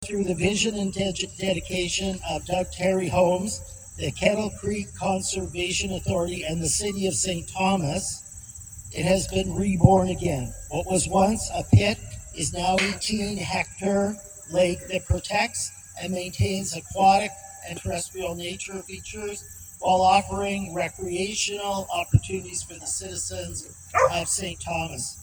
At a ceremony Tuesday morning, the Ontario Stone, Sand & Gravel Association (OSSGA) announced Lake Margaret is the recipient of their 2025 Bronze Plaque Award.